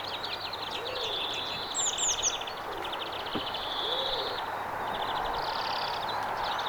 sepelkyyhkykoiraan lähikosinta ääntelyä, 2
sepelkyyhkykoiraan_kosinta-aania_namakin.mp3